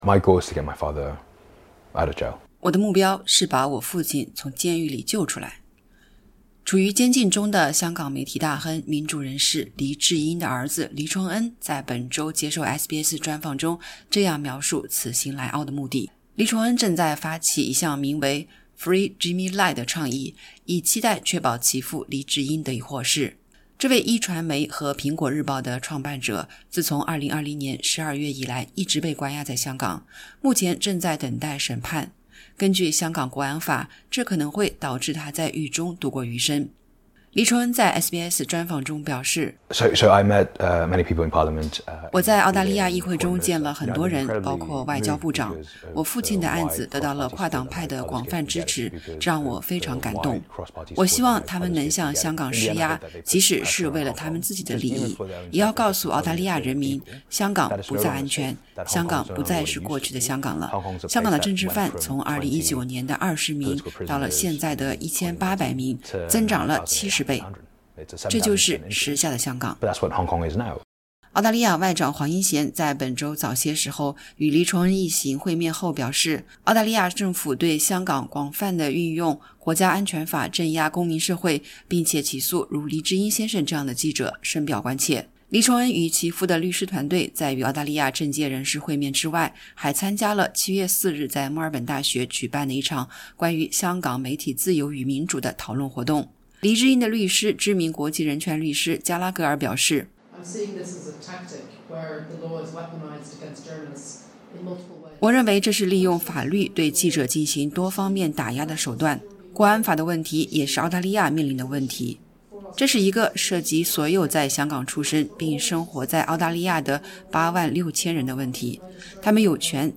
在SBS专访中这样描述此行来澳的目的